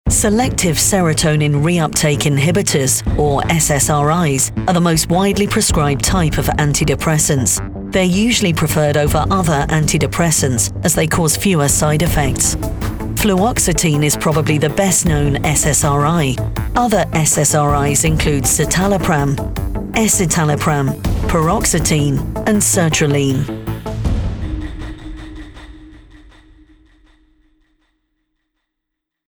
Female
English (British)
Yng Adult (18-29), Adult (30-50)
Broadcast quality home studio with fast turnaround and many happy regular clients!
Medical/Technical Narration